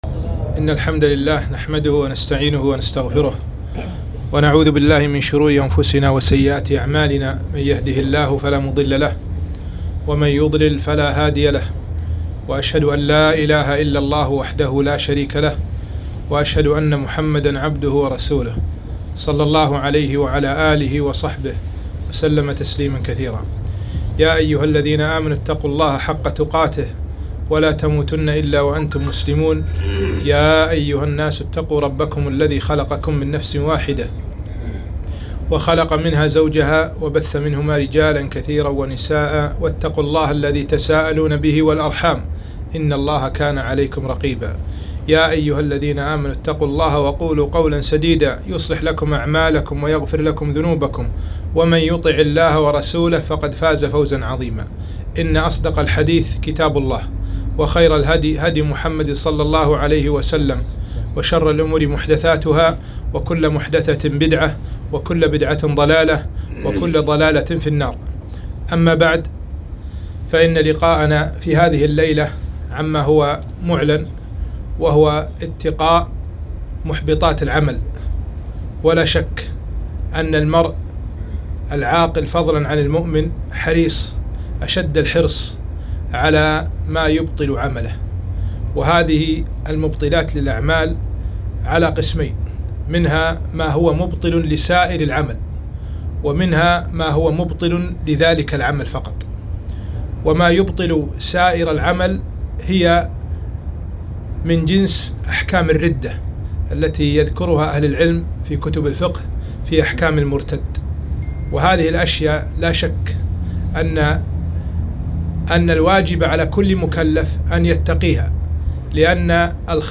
محاضرة - اتقاء محبطات الأعمال